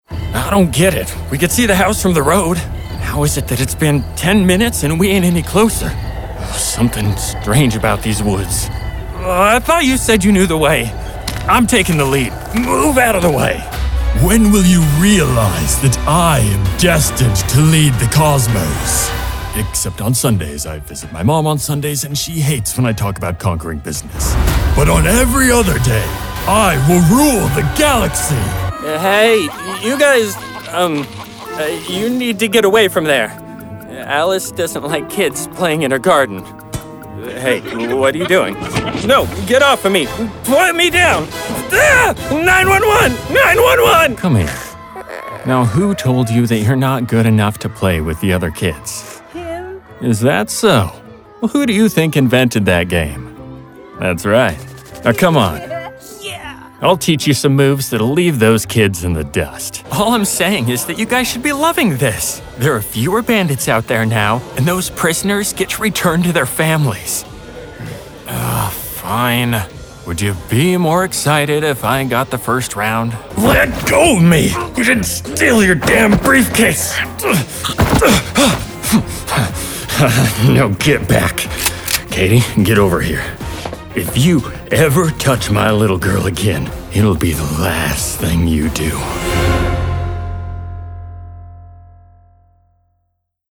Story-driven voice actor providing broadcast quality voice over for video games, animation, commercials, E-learning, narration, promotional videos, and more.
Character demo